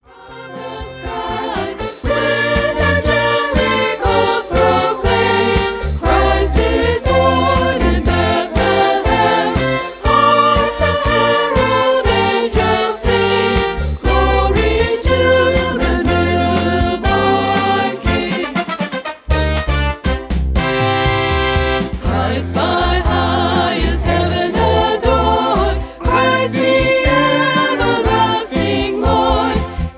traditional Christmas song